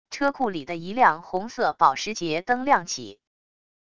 车库里的一辆红色保时捷灯亮起wav音频